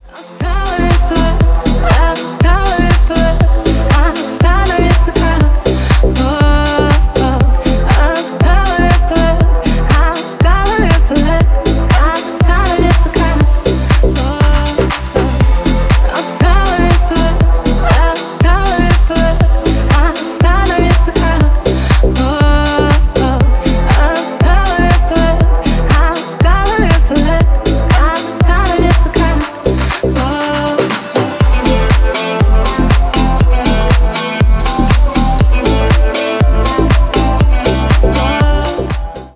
клубные
поп , ремиксы